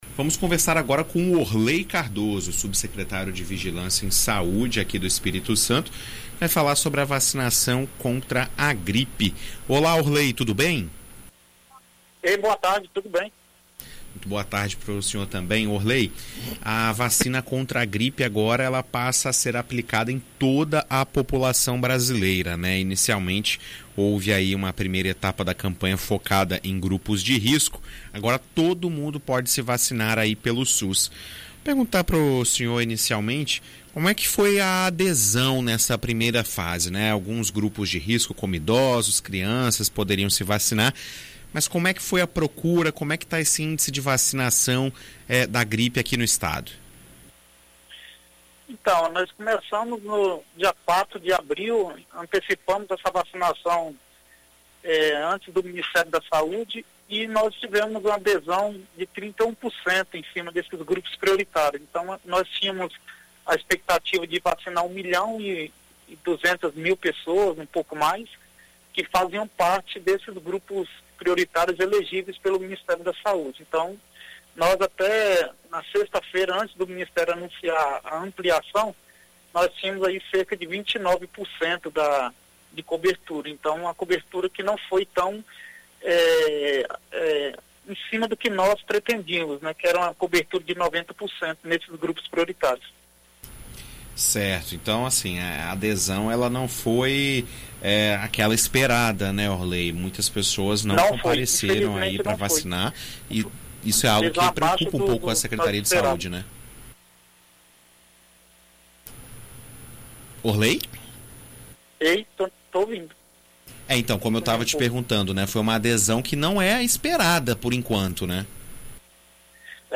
Em entrevista à BandNews FM ES nesta terça-feira (16) o subsecretário de Vigilância em Saúde da Secretaria Estadual de Saúde, Orlei Cardoso, fala sobre o assunto.